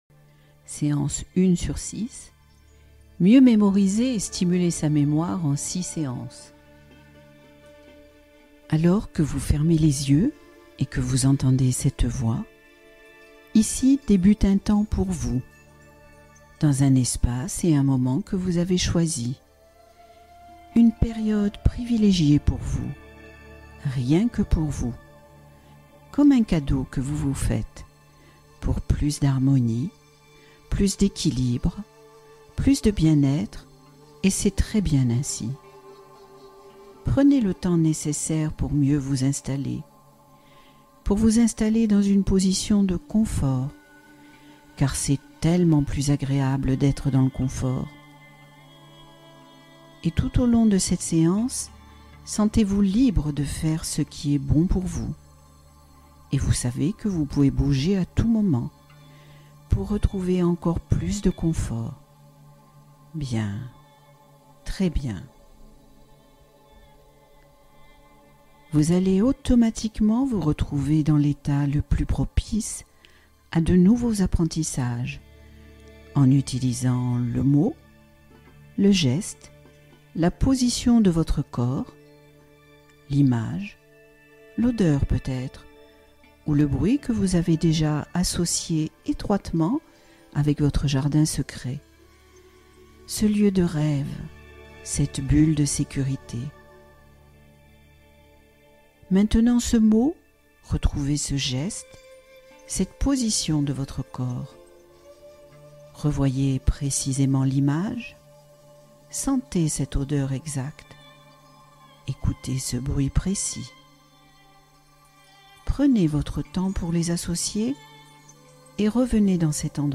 Retrouver la joie de vivre : hypnose en 6 étapes